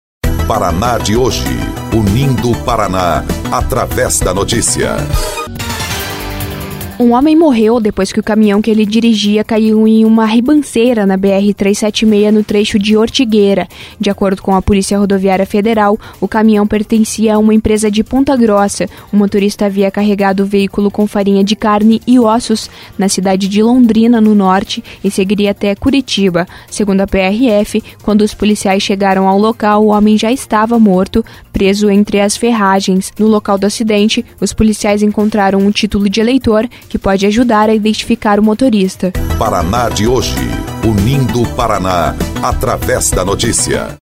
11.07 – BOLETIM – Homem morre após caminhão cair em ribanceira, em Ortigueira
11.07-–-BOLETIM-–-Homem-morre-após-caminhão-cair-em-ribanceira-em-Ortigueira.mp3